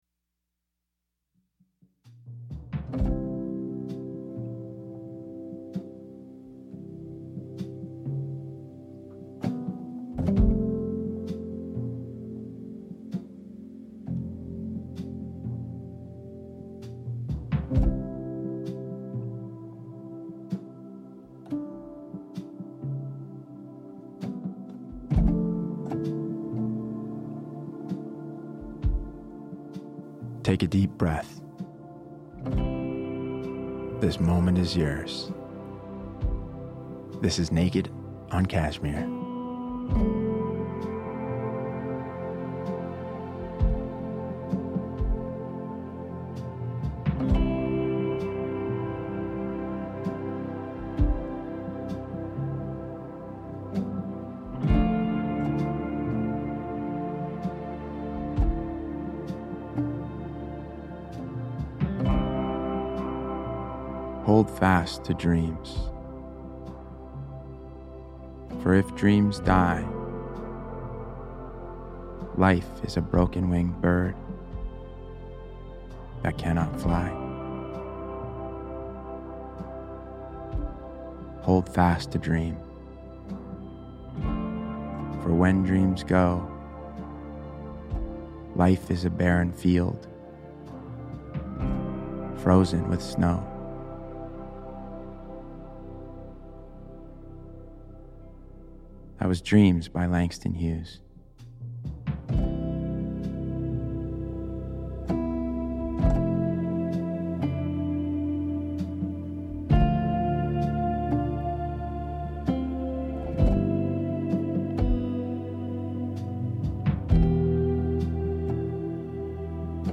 Atticus presents a daily poetry reading